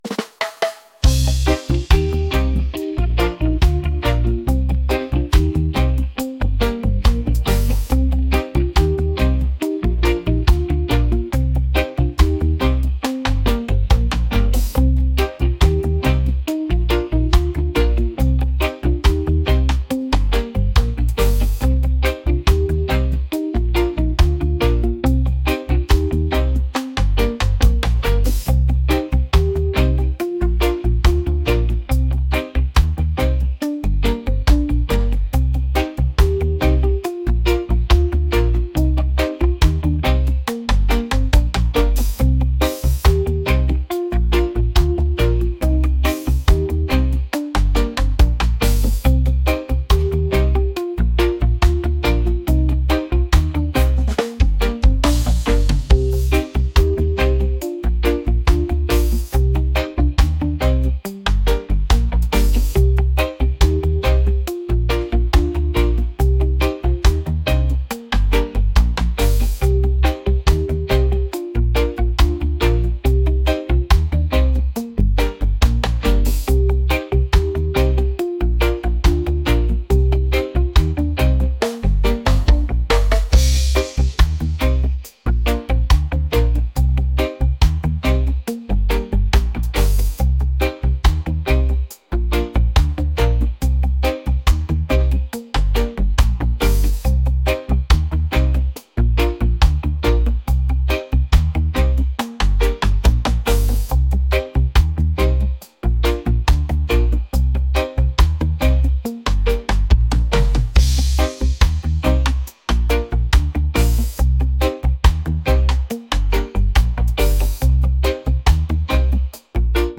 reggae | ska | rock